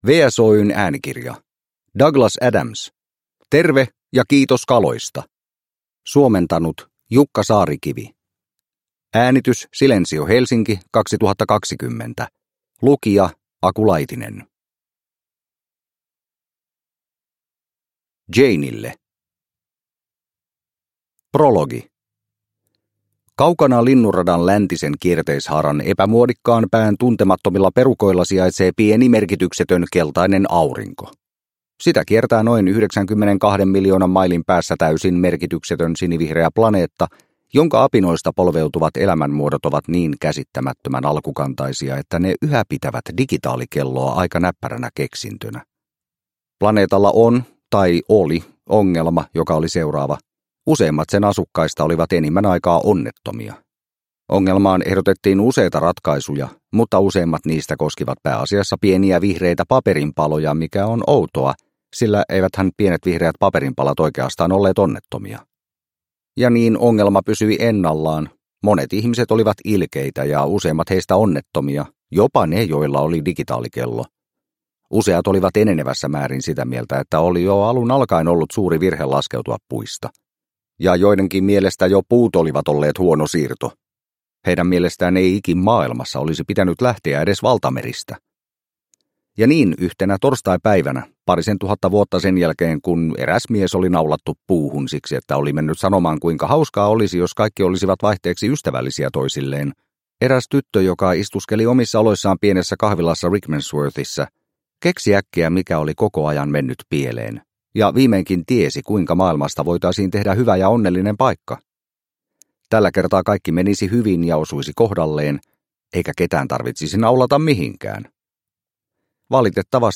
Terve, ja kiitos kaloista – Ljudbok – Laddas ner